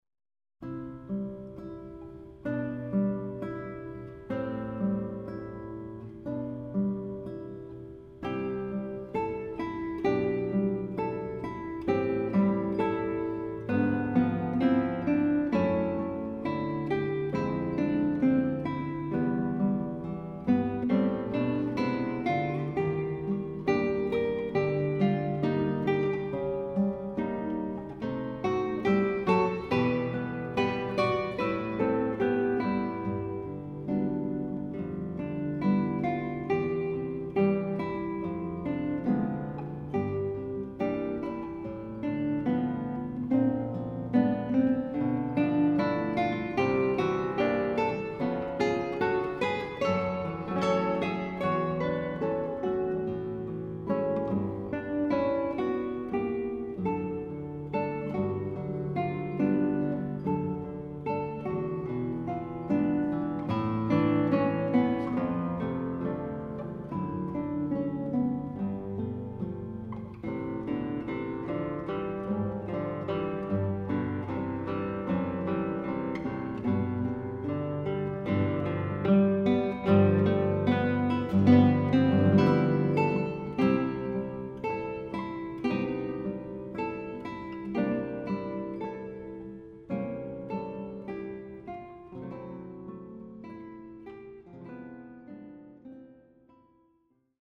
Guitar
tongue-in-cheek musical parodies